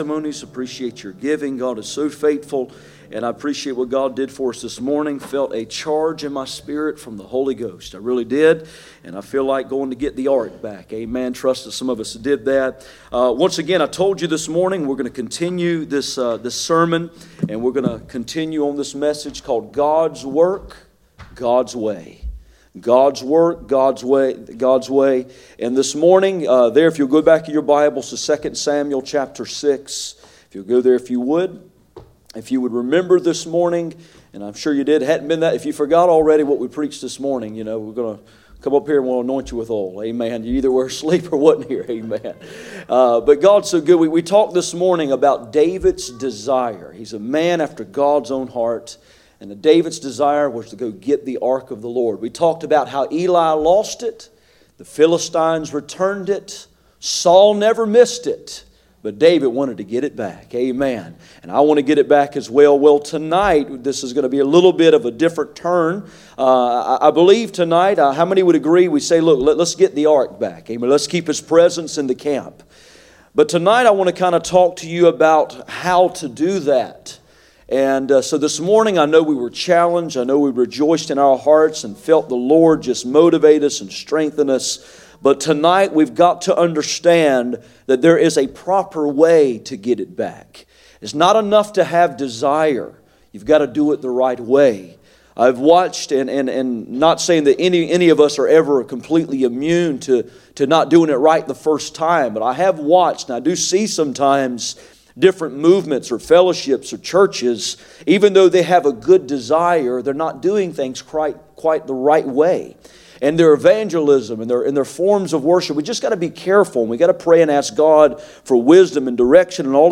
None Passage: 1 Chronicles 13:1-3, 1 Chronicles 15:1-2 Service Type: Sunday Evening